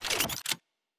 Weapon 05 Reload 2 (Laser).wav